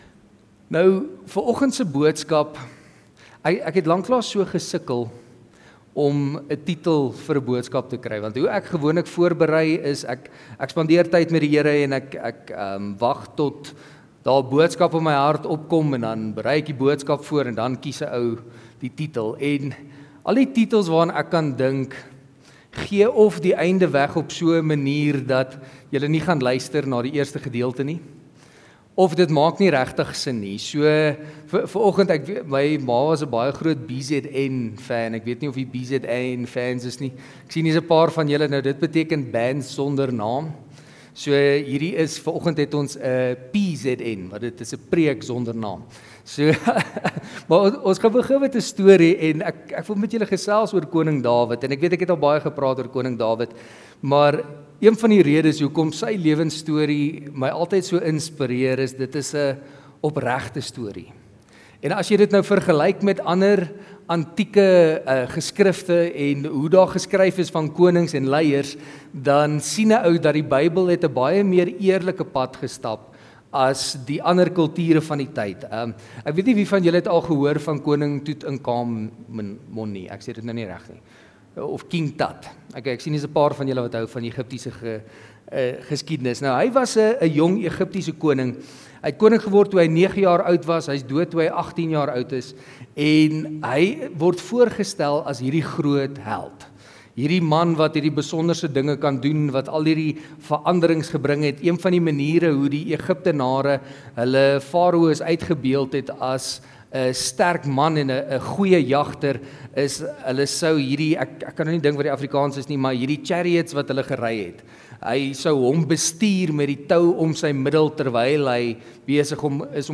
Preek Sonder Naam PZN